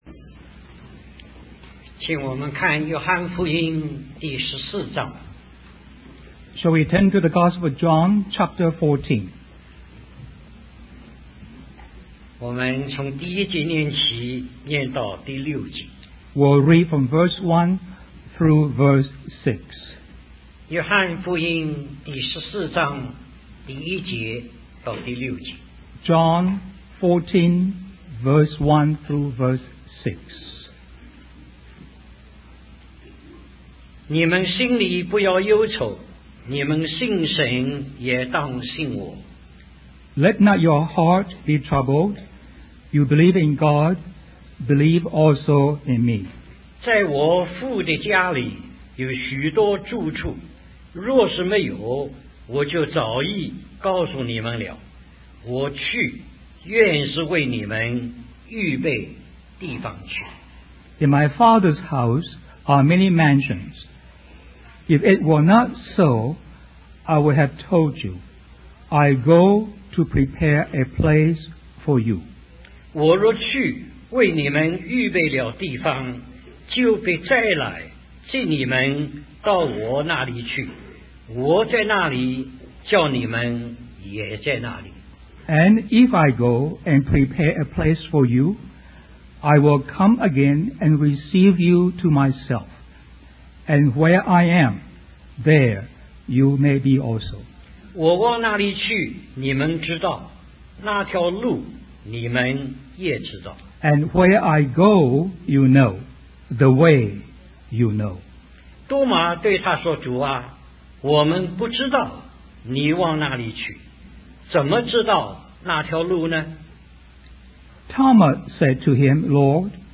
In this sermon, the preacher emphasizes the importance of knowing our destination in order to understand the way. He uses examples of flowers and pets to illustrate the temporary nature of earthly life. The preacher then reads from the Gospel of John, specifically chapter 14, verses 1-6, where Jesus declares himself as the way, the truth, and the life.